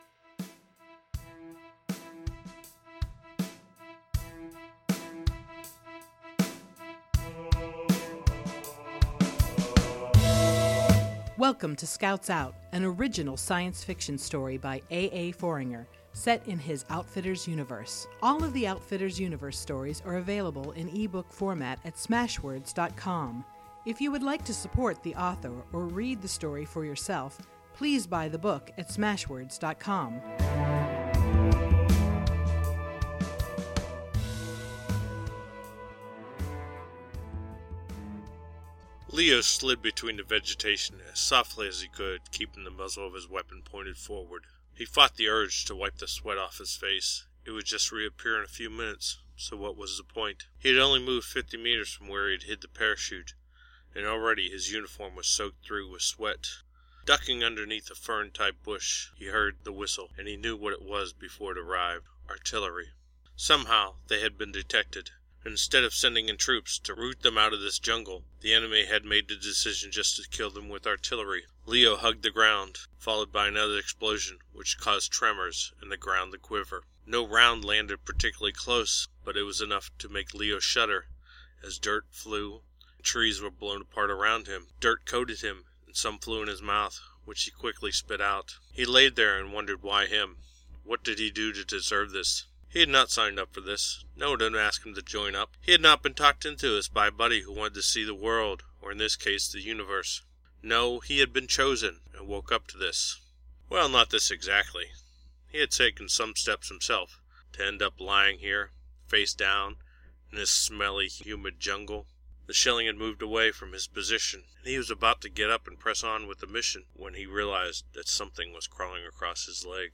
So if you ever wanted to know what my voice sounds like, it will be me reading the book.